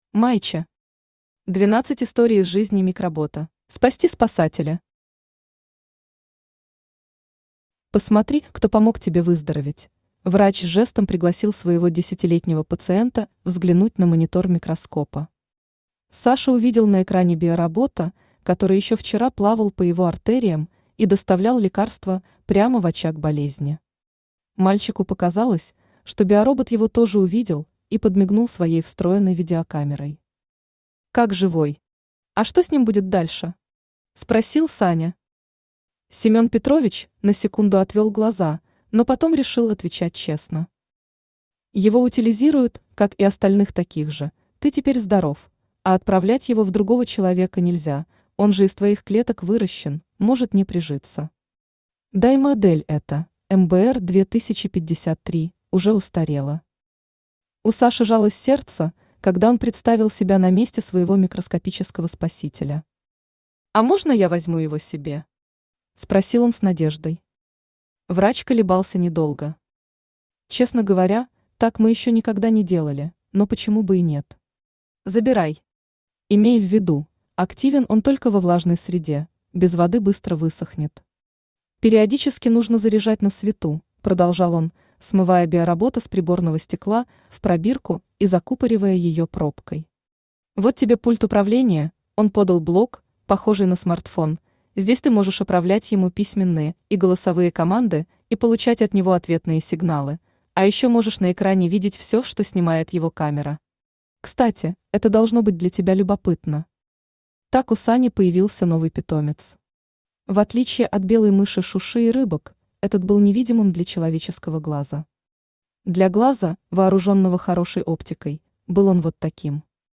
Аудиокнига Двенадцать историй из жизни микробота | Библиотека аудиокниг
Aудиокнига Двенадцать историй из жизни микробота Автор Майче Читает аудиокнигу Авточтец ЛитРес.